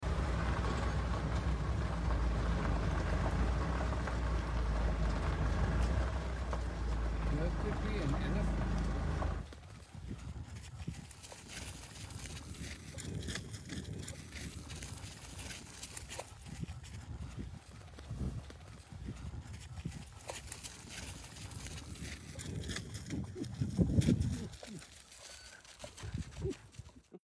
Hyenas With Elephant Leg#hyena Sound Effects Free Download